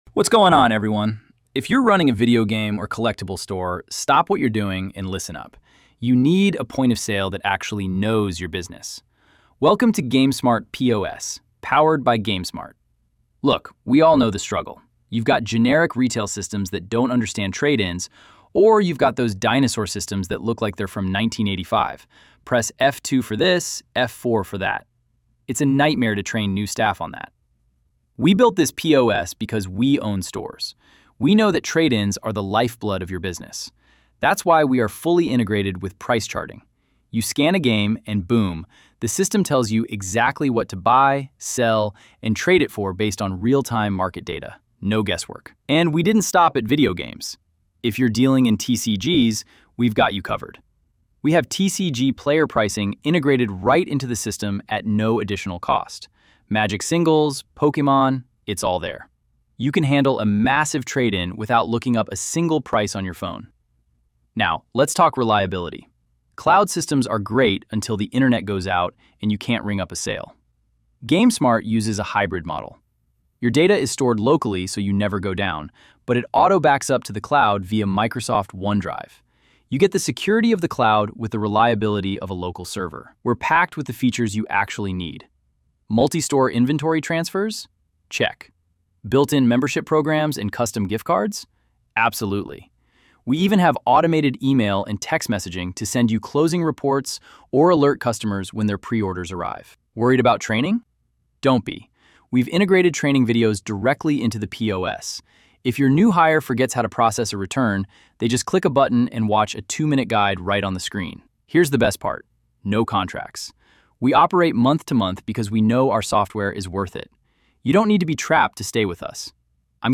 Press play for a narrated walkthrough of what makes the system different.